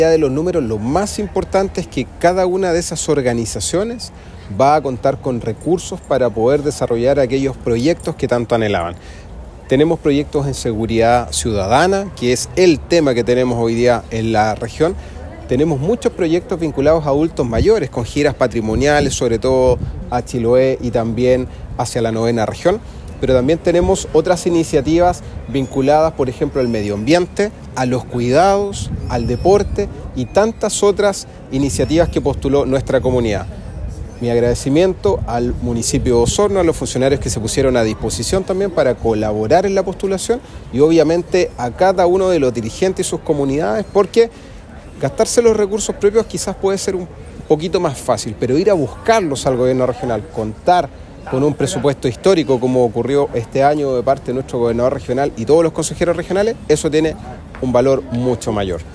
Ayer jueves, en la Casa del Folclor de Osorno, se llevó a cabo la Ceremonia de Firma de Convenio del Fondo Comunidad, donde 193 organizaciones territoriales y funcionales de la comuna recibieron recursos destinados a la ejecución de proyectos en diversas áreas, como Seguridad Ciudadana, Adultos Mayores, Fondo Social, entre otras.
El Concejal Miguel Arredondo enfatizó que los proyectos aprobados impulsarán avances en seguridad, equidad para los adultos mayores y en el ámbito deportivo, reconociendo el esfuerzo de los dirigentes sociales de la comuna.